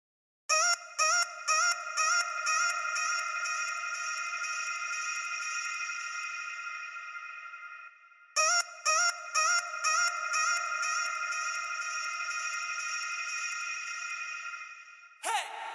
描述：一段非常愚蠢的音乐和声音效果。
Tag: 音乐 古怪的 愚蠢的 动画 高飞 波尔卡